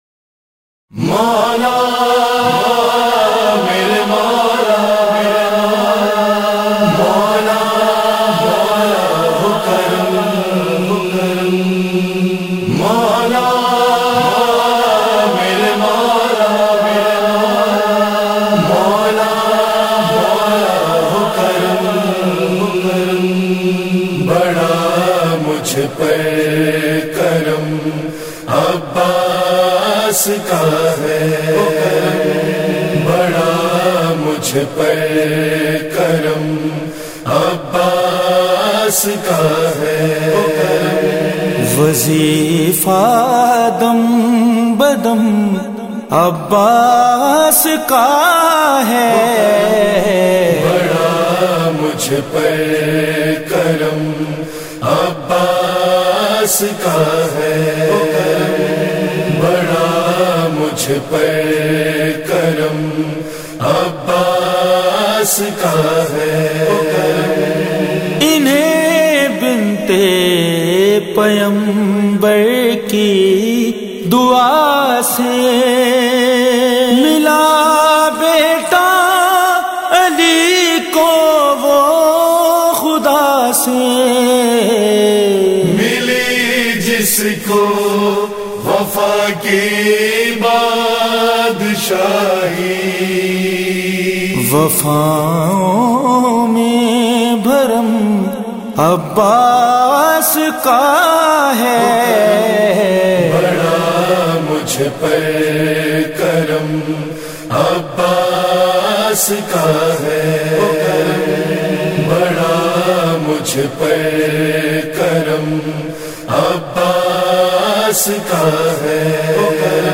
منقبتیں اور قصیدے